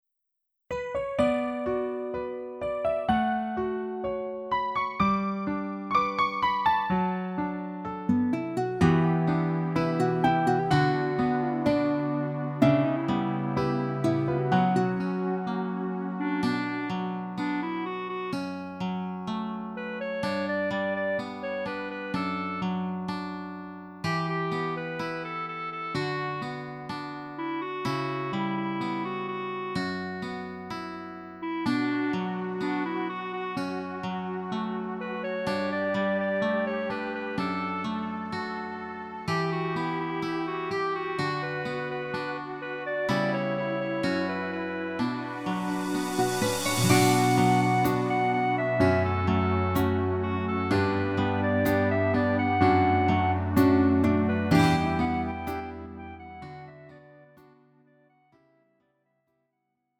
음정 원키 3:36
장르 가요 구분 Lite MR